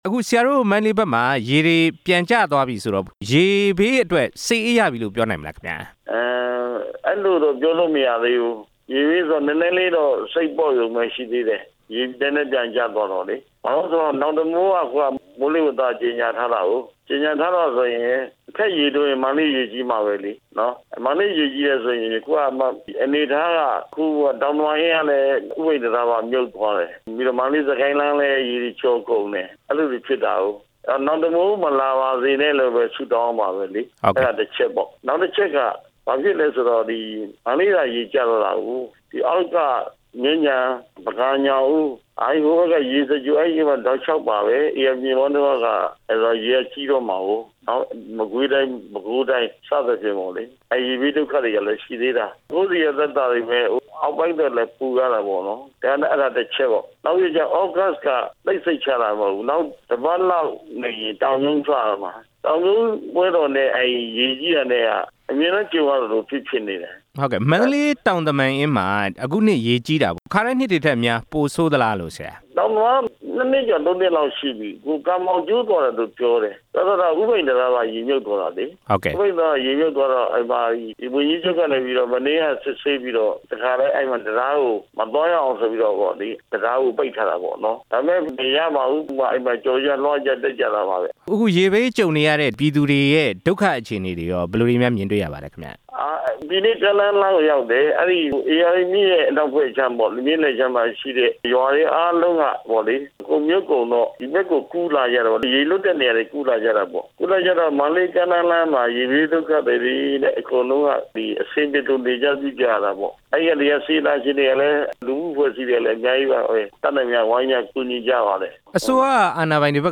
မန္တလေးရေဘေး နောက်ဆုံးအခြေအနေ မေးမြန်းတင်ပြချက်